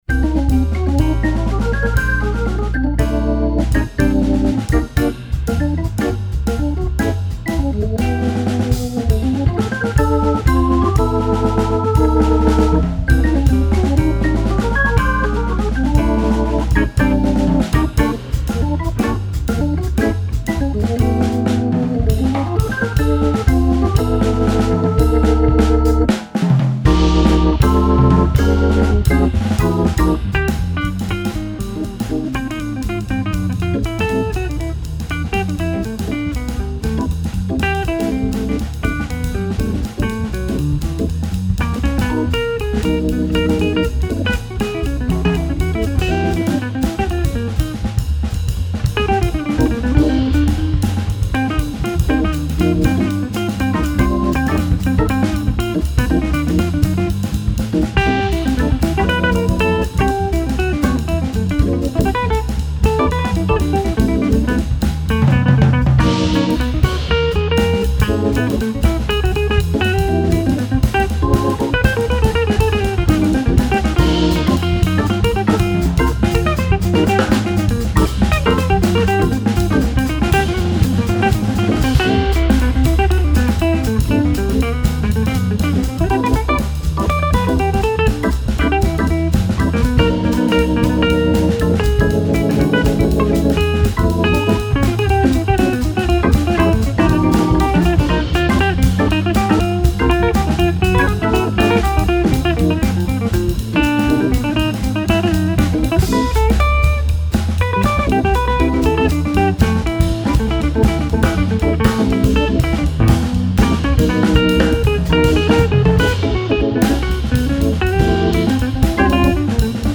Hammond organ
The seven selections include uptempo explorations
keeps the music swinging hard
modern jazz organ trio
FILE: Jazz